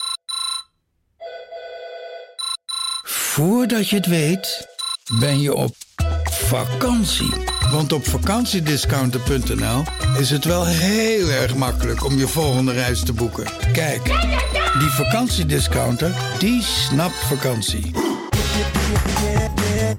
Een effectieve overgang waarin de luisteraar wordt meegenomen van werkgeluiden naar ontspannen vakantiegeluiden, wat sterk inspeelt op het verlangen van de doelgroep.
De_VakantieDiscounter_-_Salsa_RingRing.mp3